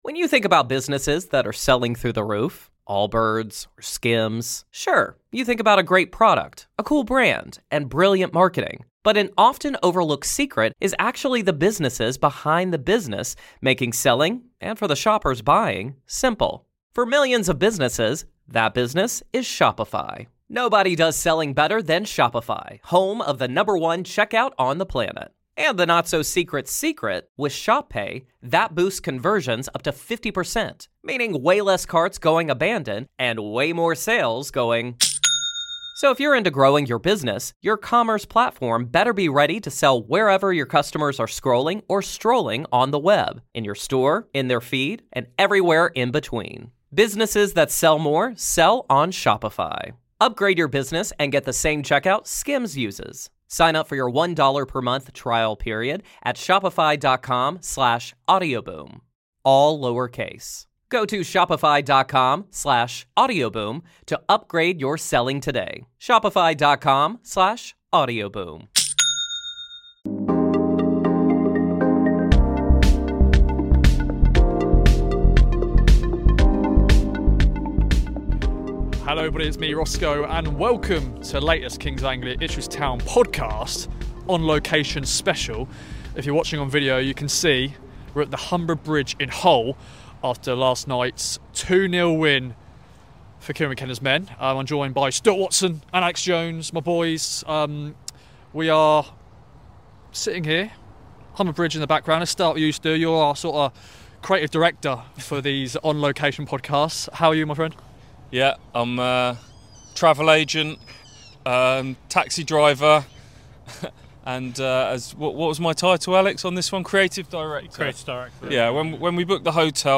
Kings of Anglia: On location in Hull as Town tame the Tigers 2-0
Sat in front of the Humber Bridge, the boys reflect on the victory, where Marcelino Nunez and Chuba Akpom struck to secure victory.